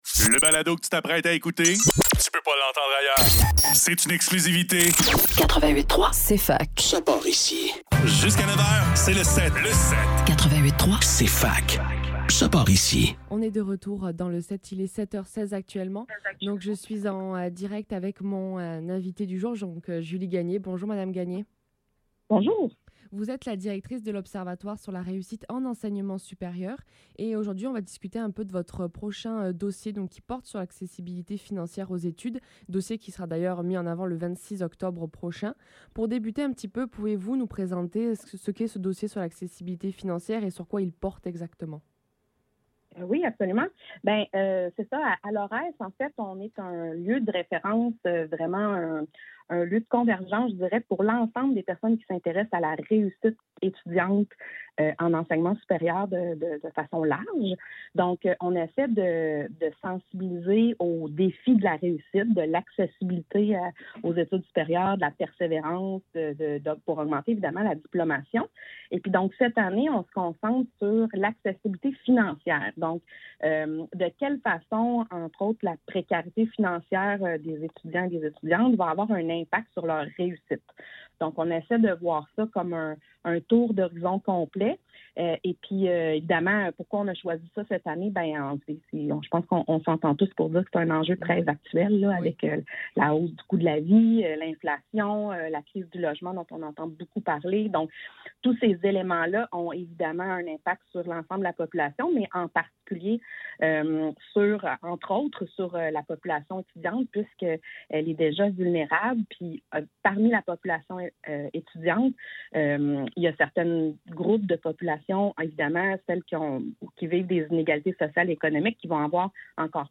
Le SEPT - Entrevue